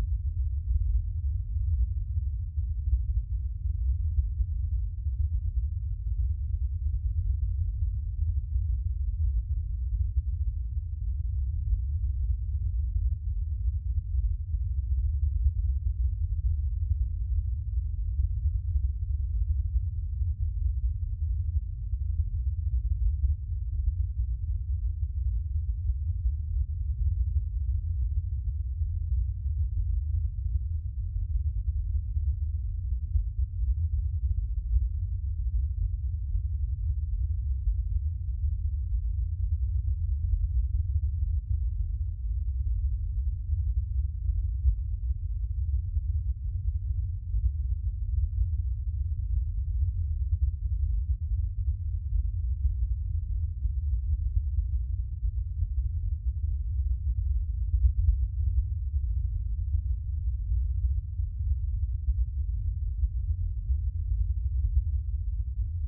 63Hz_WAVファイル（オクターブバンドノイズ）（約0.4MB）
空気の振動感があります。
再生しても聞こえないスピーカーもあります。
T63HZ.WAV